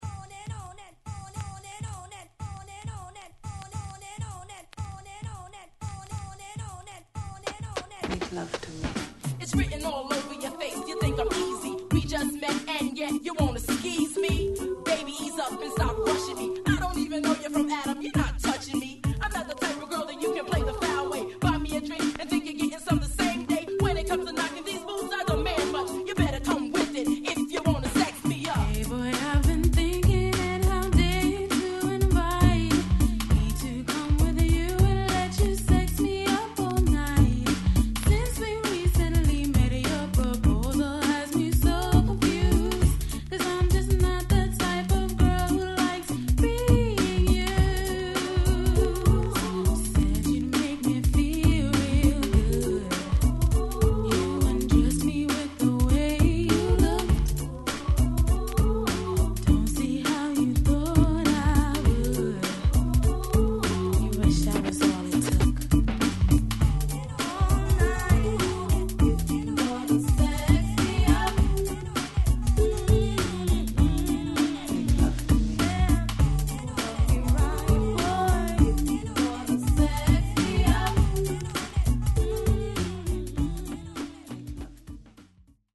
・ 45's R&B